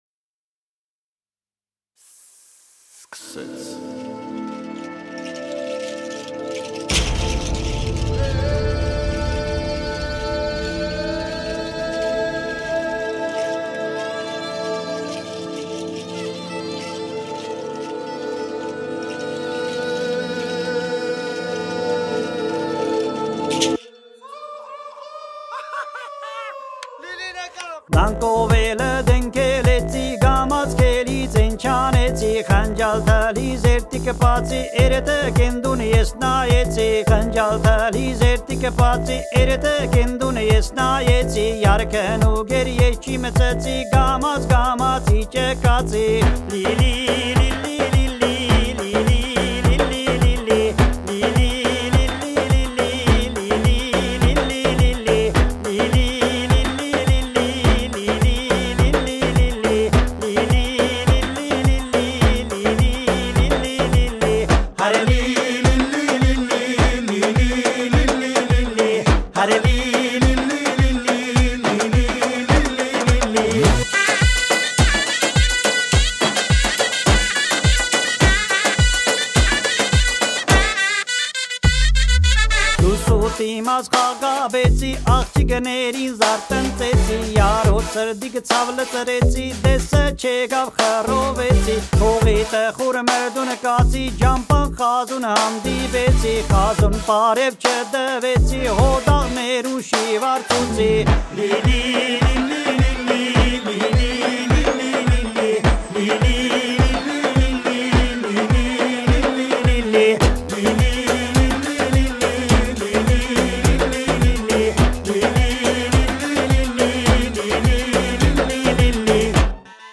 This fun folk song in the melodious Sasuntsi dialect will have you up and dancing in no time.